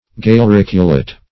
Search Result for " galericulate" : The Collaborative International Dictionary of English v.0.48: Galericulate \Gal`er*ic"u*late\, a. [L. galericulum, dim. of galerum a hat or cap, fr. galea helmet.]